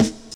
Snare (4).wav